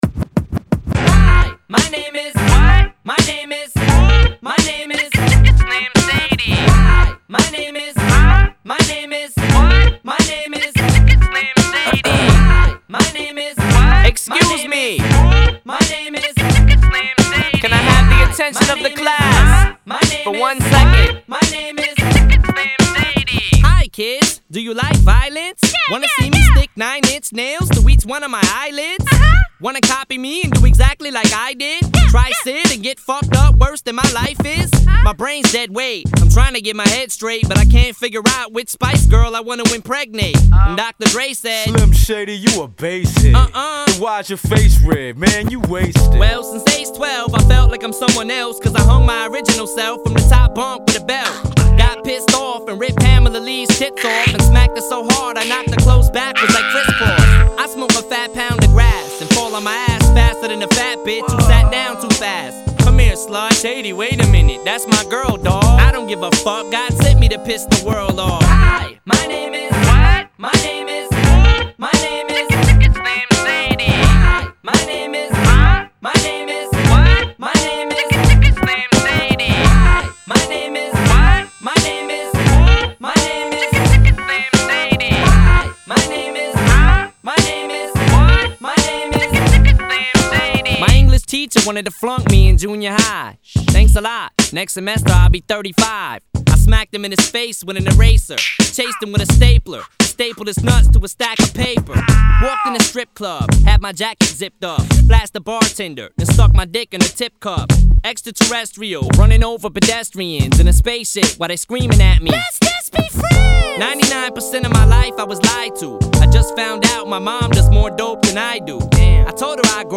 Hip-Hop, Rap